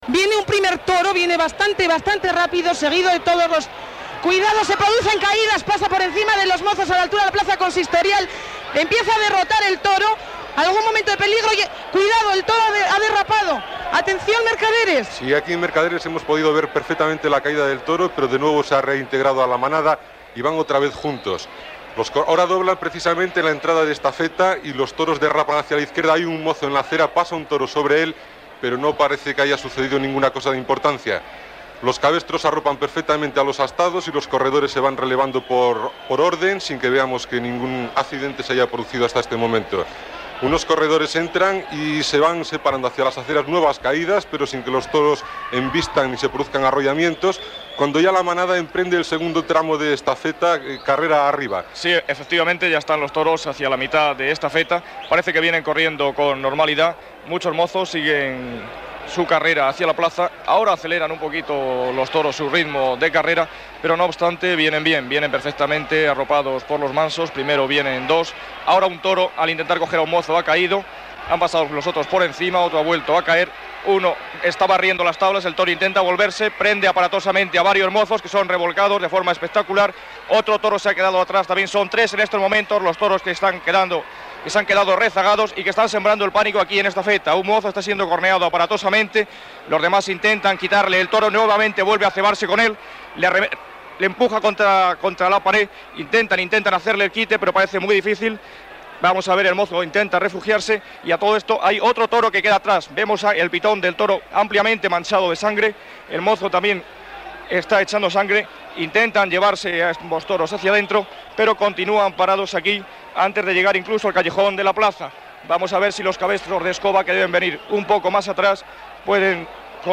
Transmissió des de Pamplona del "encierro" dels braus d'Osborne. Identificació del programa, sintonia de l'emissora
Informatiu